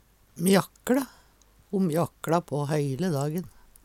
mjakkLe - Numedalsmål (en-US)
DIALEKTORD PÅ NORMERT NORSK mjakkLe stomle Infinitiv Presens Preteritum Perfektum mjakkLe mjakkLar mjakkLa mjakkLa Eksempel på bruk Ho mjakkLa på heile dagen.